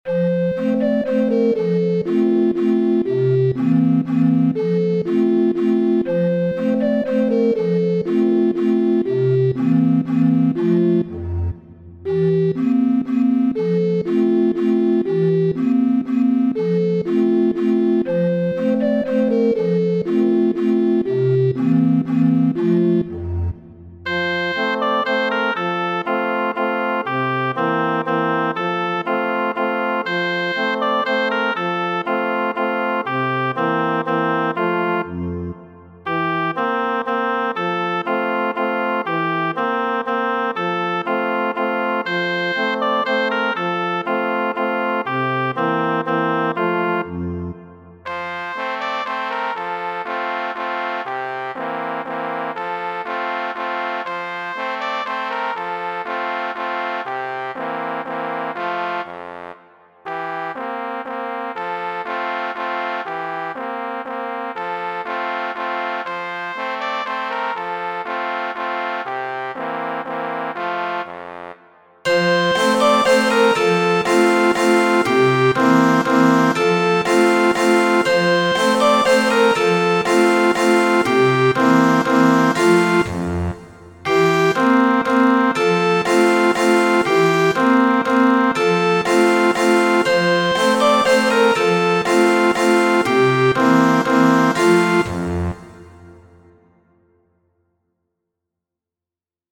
O dolĉa Aŭgustin',, germana infankanto, kiu prezentas la kolorojn de ĉiu muzika familio.